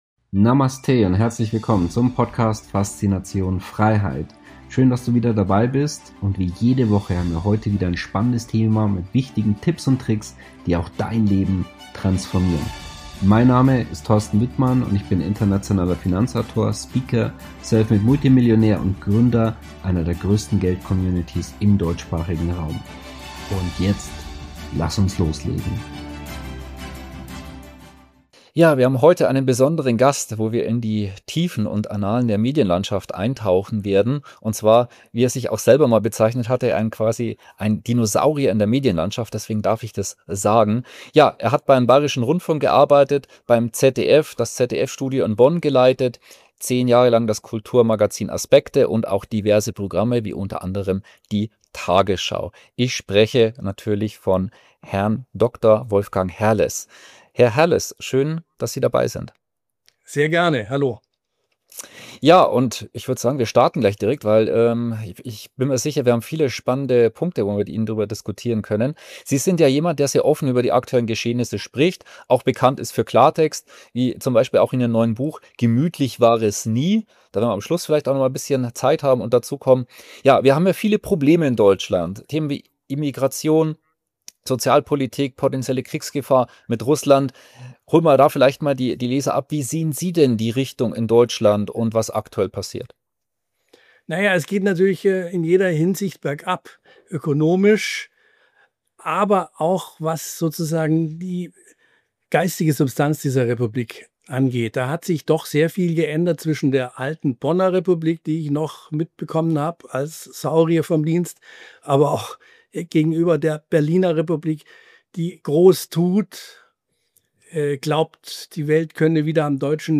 In diesem pointierten Interview mit Wolfgang Herles (ehem. ZDF-Studioleiter, Autor) sprechen wir über Konformismus, Moralisierung und die Entpolitisierung des Diskurses – inkl. harter Einblicke in die Medien-/Politik-Verflechtung, die Rolle der Öffentlich-Rechtlichen und den Preis für echte Meinungsvielfalt. Du erfährst, warum Herles von einem „Gouvernantenstaat“ spricht, weshalb Skepsis eine Bürger-Tugend ist, und welche Schritte du heute gehen kannst, um geistig wie finanziell unabhängiger zu werden.